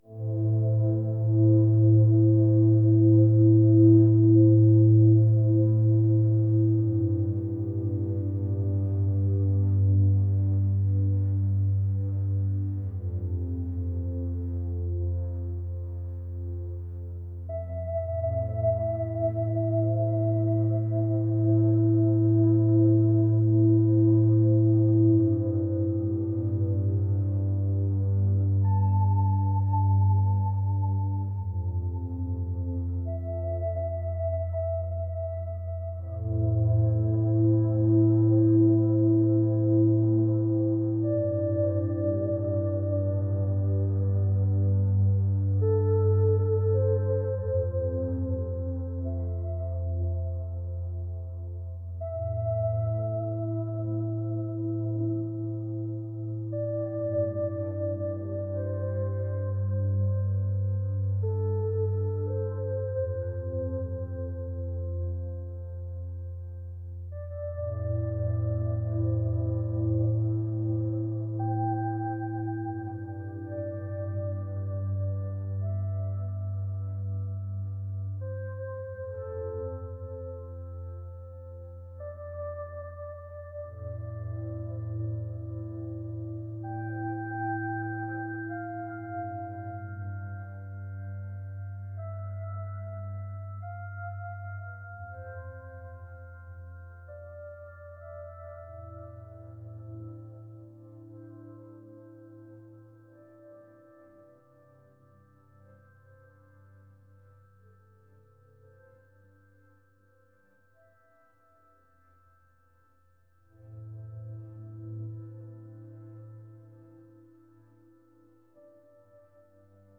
ambient | ethereal